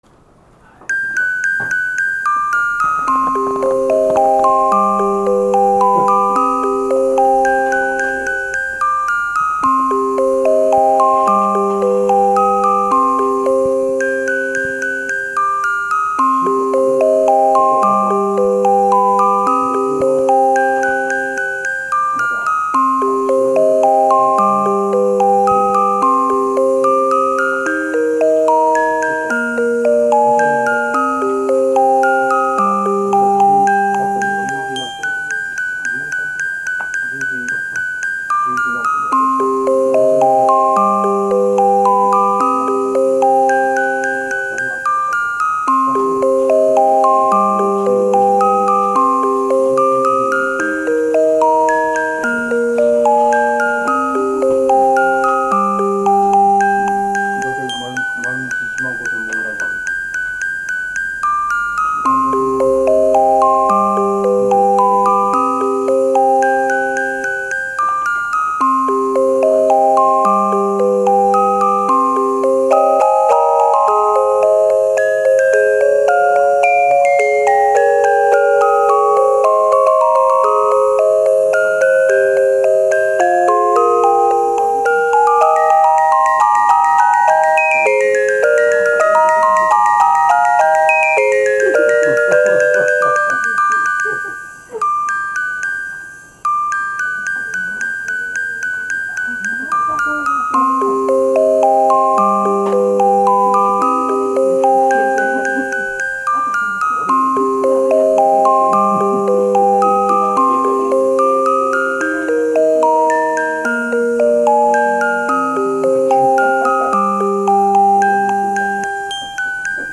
電子オルゴール
私が作ったオルゴールは８和音まで計算出来ます。
アタック部は本物のオルゴールの音を録音したもの。 サステイン部はサイン波です。
スピーカーで聞くと自然なフィルタ効果で感じにくいのですが、イヤホンで聞くとノイズが気になります。